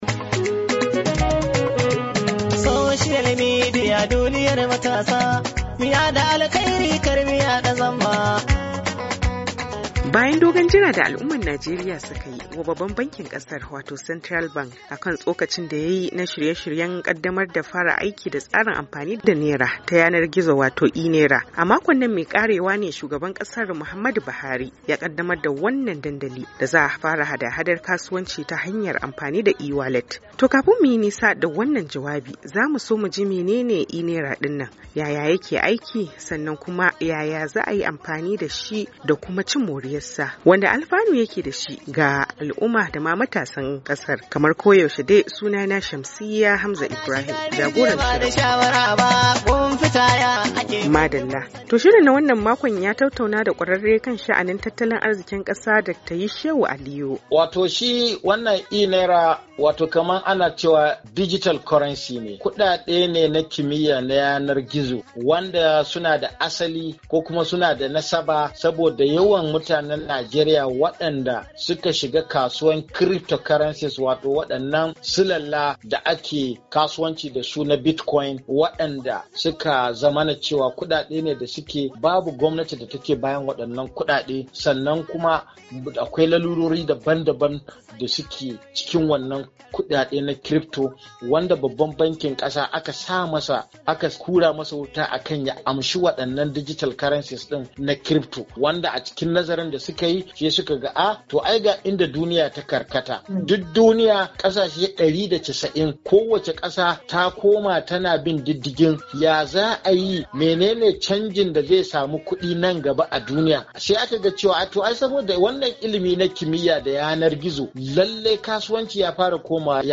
MATASA A DUNIYAR GIZO: Tattaunawa Kan Kudin E-Naira Da Najeriya Ta Kaddamar, Oktoba 30, 2021